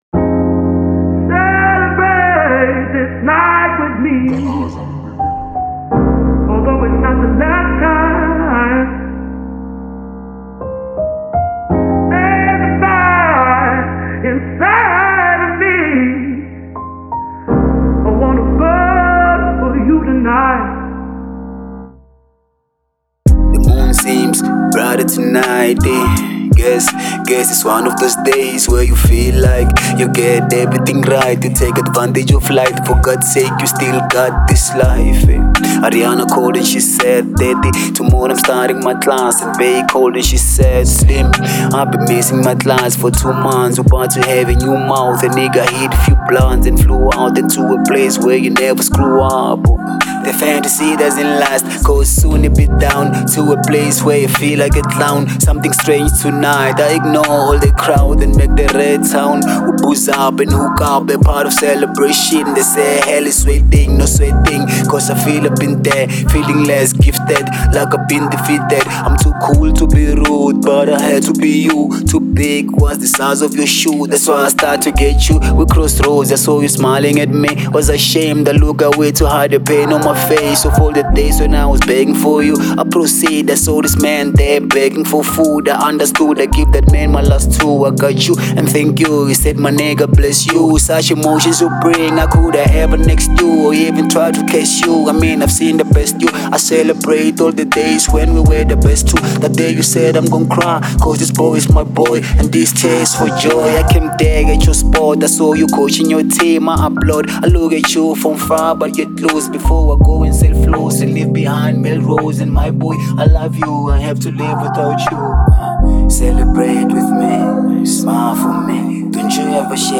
02:20 Genre : Hip Hop Size